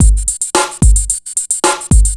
TSNRG2 Breakbeat 011.wav